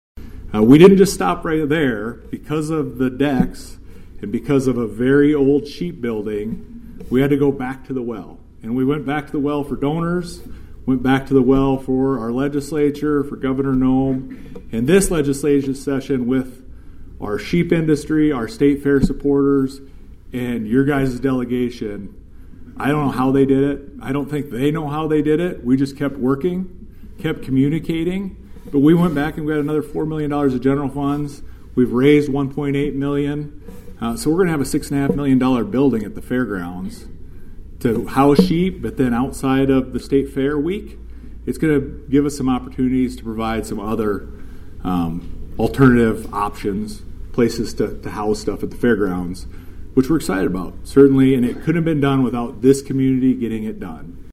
At the Lincoln Day Dinner held at the Huron Events Center last Thursday, South Dakota secretary of the Department of Agriculture and Natural Resources, Hunter Roberts was the keynote speaker.